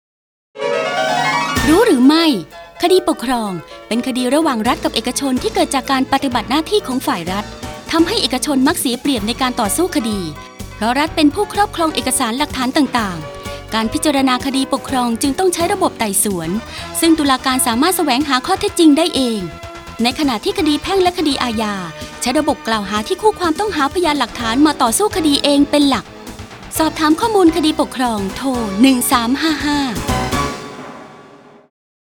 สารคดีวิทยุ ชุดคดีปกครองชวนรู้ ตอนศาลคู่ดีอย่างไร